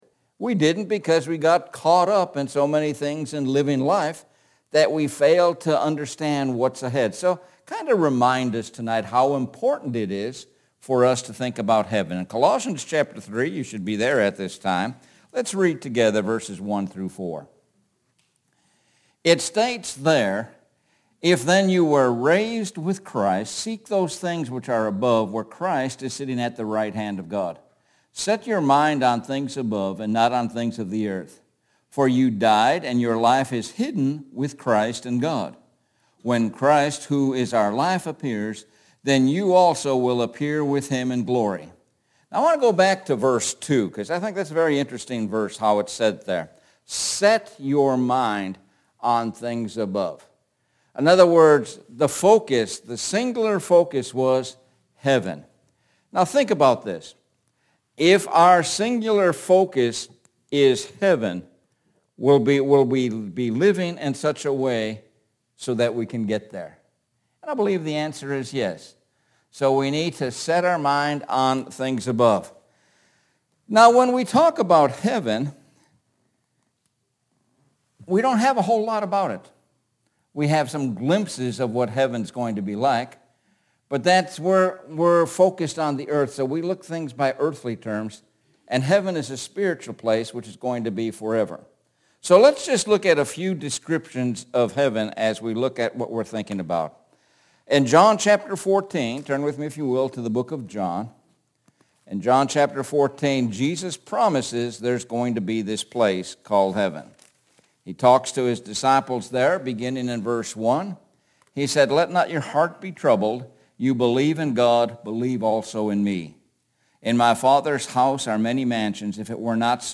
Sun PM Sermon – Destination Heaven